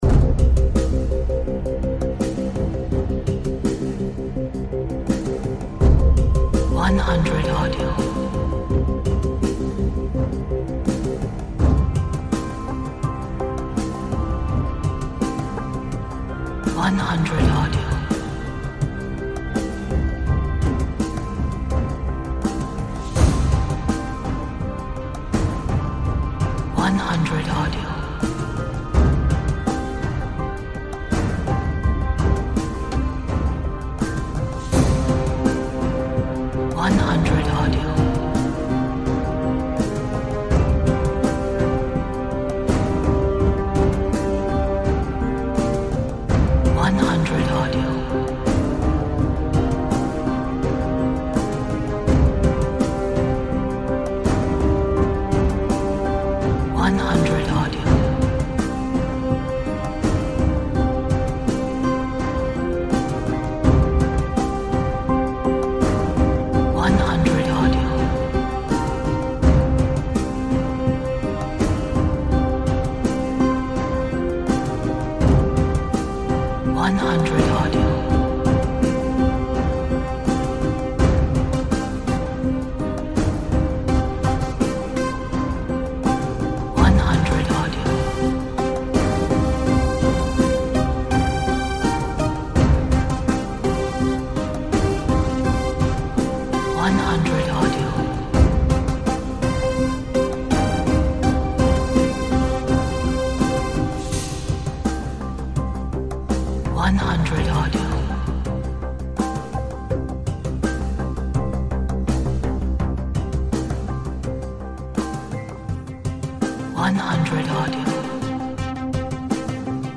音乐整体有一些悲壮，能够烘托出场景的宏大。